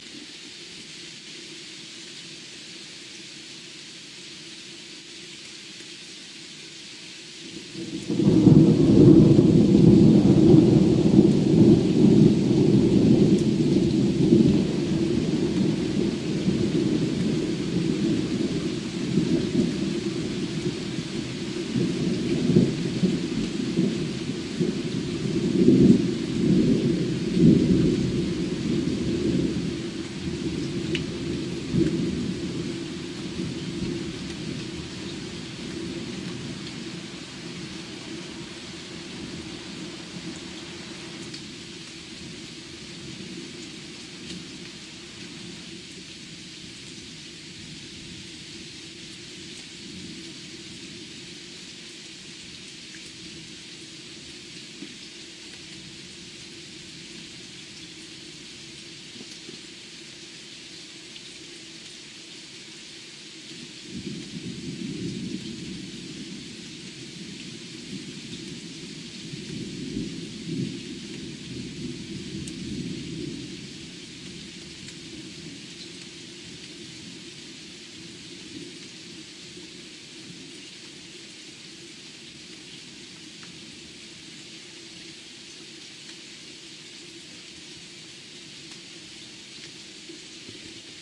2017年7月23日的雷暴之夜 " 遥远的雷声 2017年7月23日
描述：2017年7月23日（凌晨2点30分）雷暴期间，两个独立的远处雷鸣听到了倾盆大雨的声音，在我家前面被抓住了 录制于2017年7月23日至7月24日夜间，我的JVC GZR415BE摄像机内置麦克风，位于法国GrandEst的Alutace，HautRhin村庄，因为多细胞雷暴袭击了该镇。